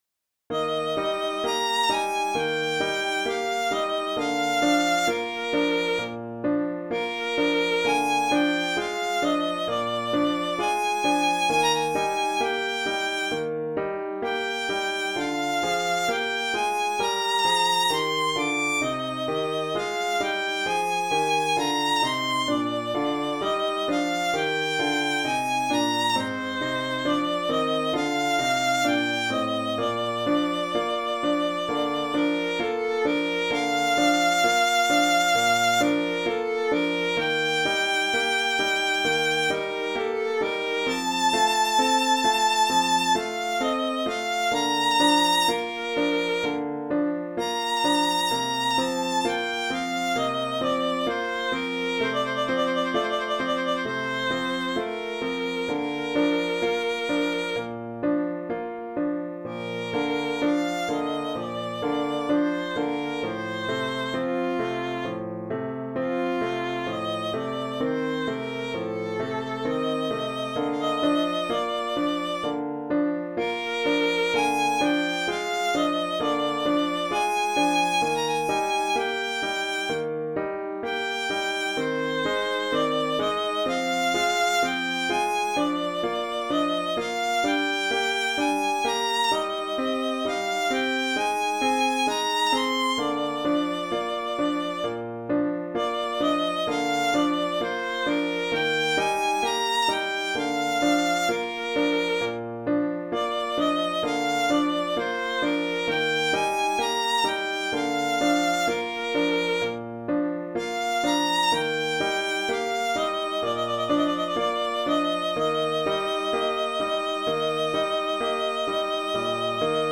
arranged for Violin and Piano